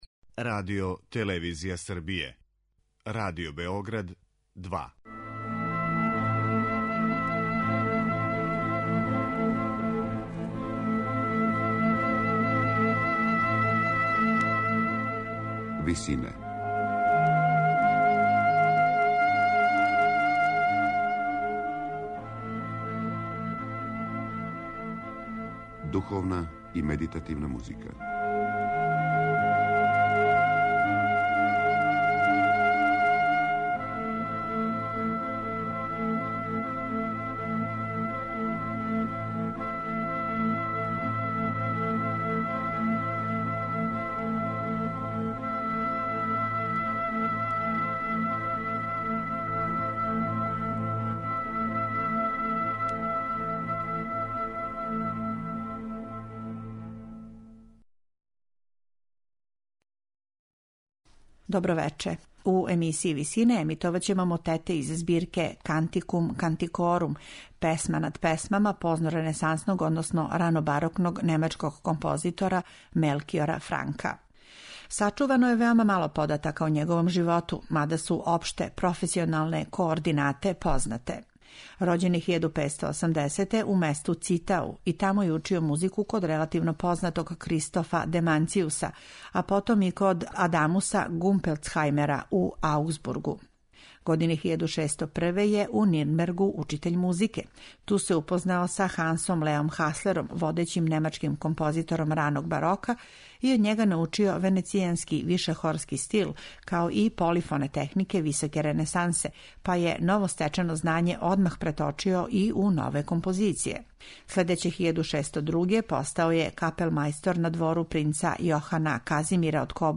Емисија духовне музике